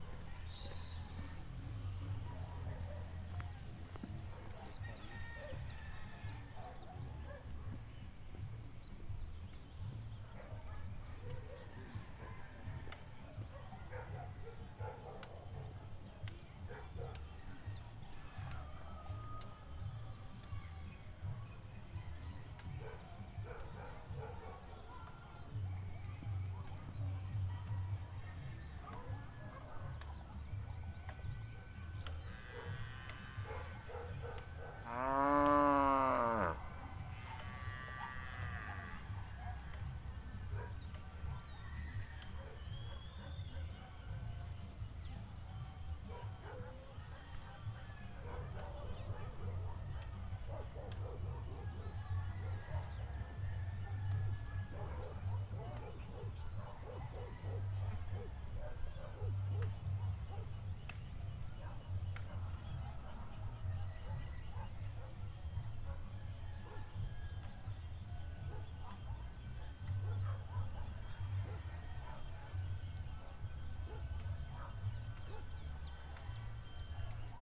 Trujillo-cancha de fútbol.mp3 (1.77 MB)
Paisaje sonoro de cancha de fútbol en Trujillo.